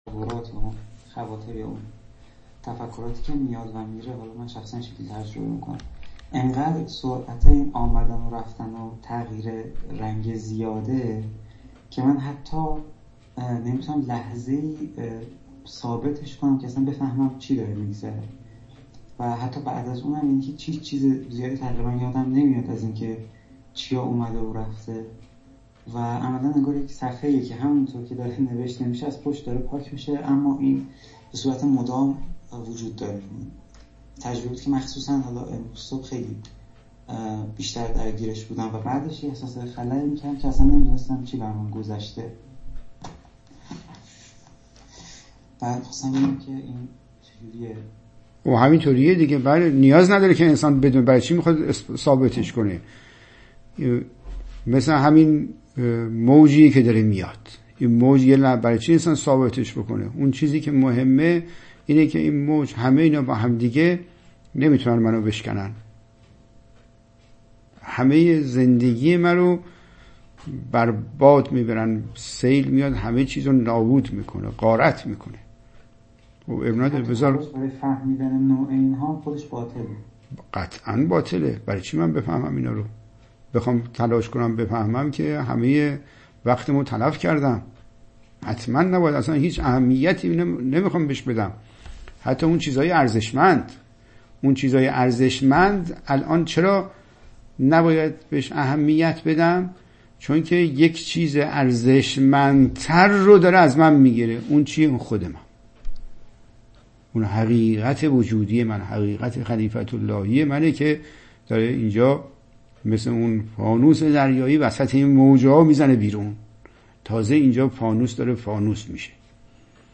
متن : پرسش و پاسخ